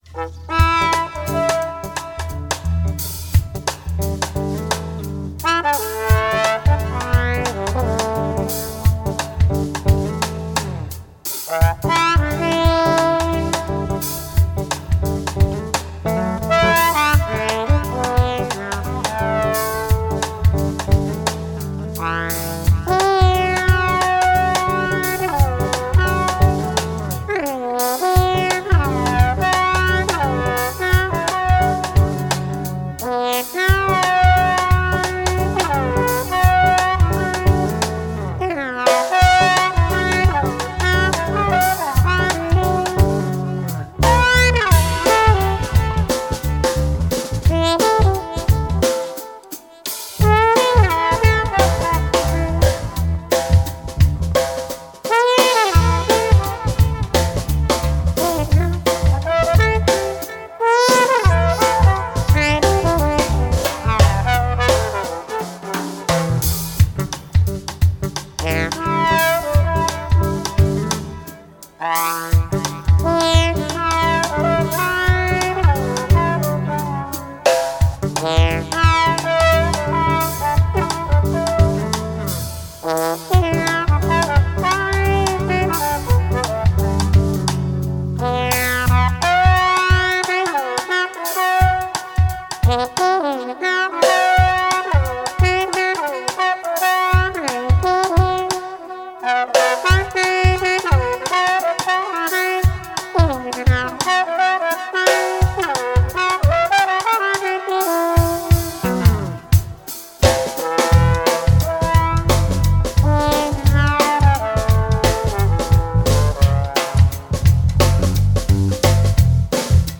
trombone / loops
bass
drums Ecouter un extrait